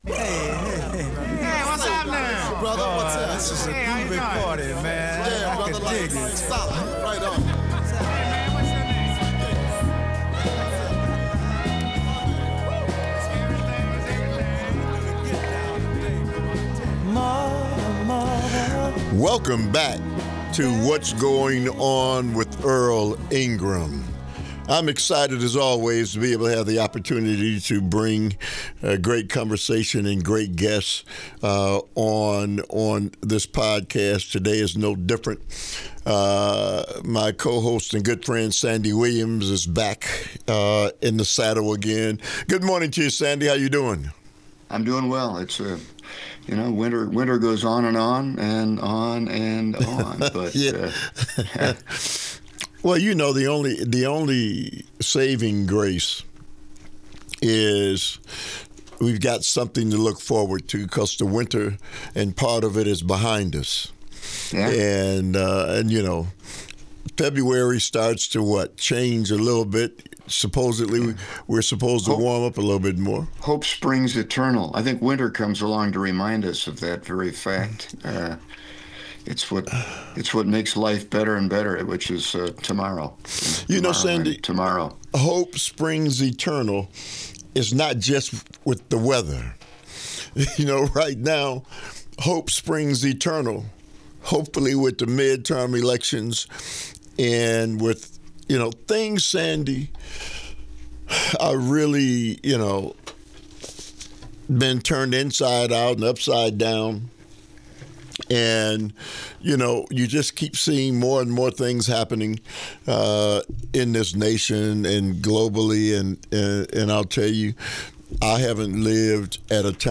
Civic Media 92.7 WMDX